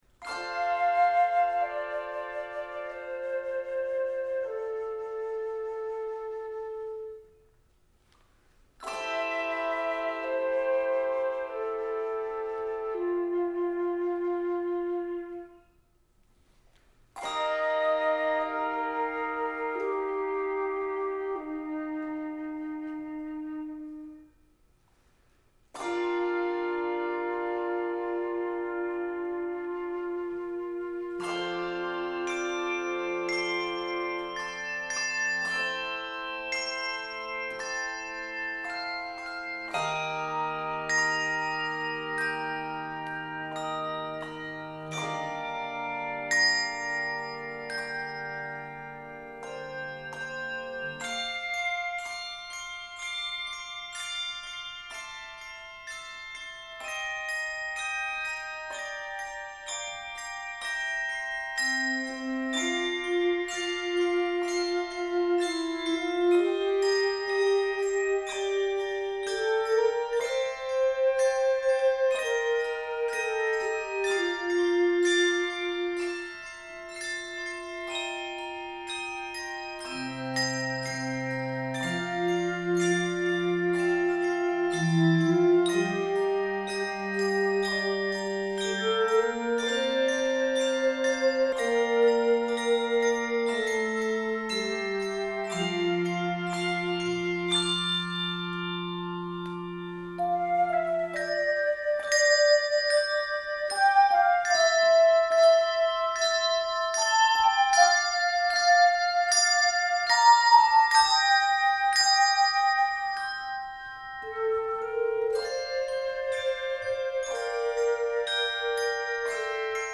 beautiful and sweet setting
Consider adding a cello if possible.
Key of F Major.